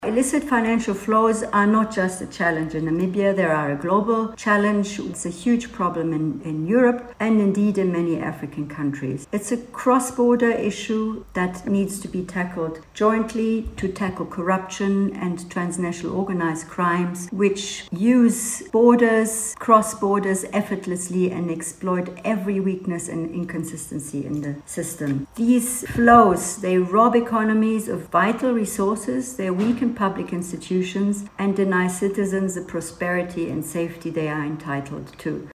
EU Ambassador to Namibia Ana Beatriz Martin said there is a need for coordinated tax governance and stronger institutional capabilities as governments confront increasingly complex financial threats.